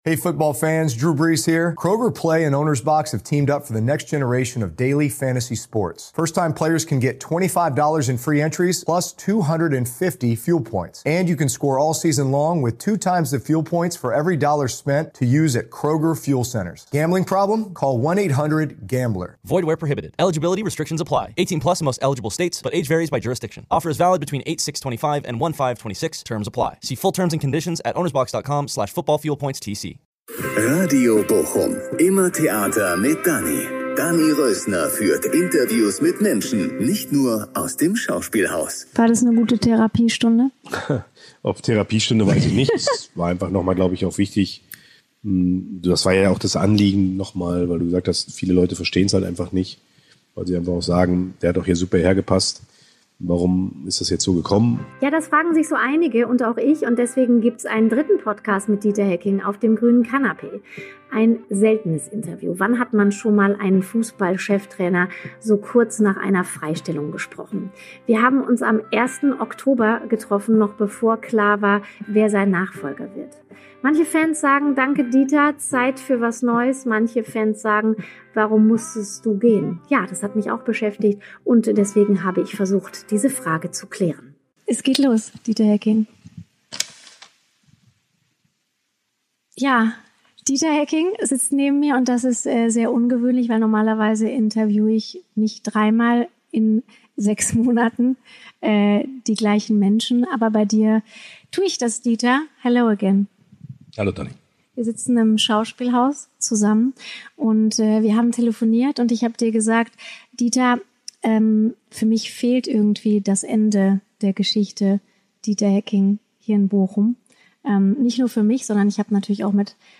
Genauer gesagt im Foyer auf dem grünen Kanapee mit Blick in die Stadt.
… continue reading 71 episode # Showbiz # Gesellschaft # Nachrichten # Radio Bochum # Interview # Schauspielhaus # Theater # Bochum